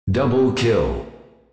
Announcer
DoubleKill.wav